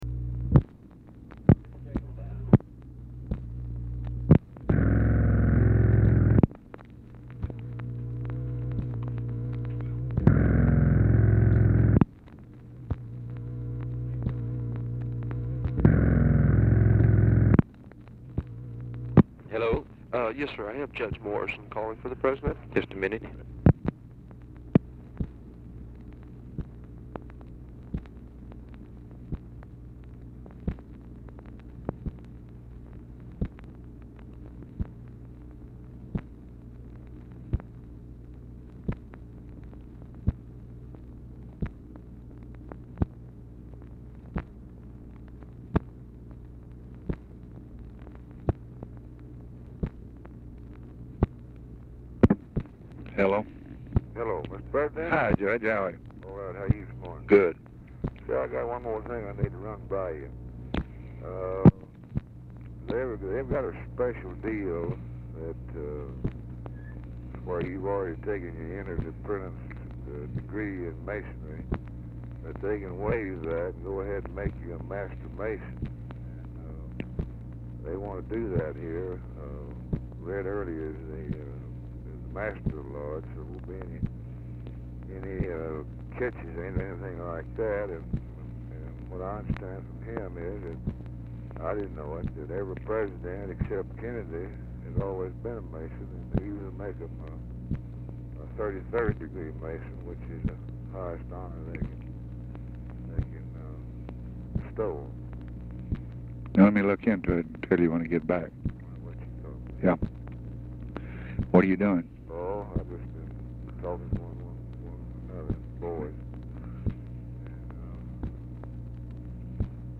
Telephone conversation
SOME STATIC IN RECORDING
Format Dictation belt
LBJ Ranch, near Stonewall, Texas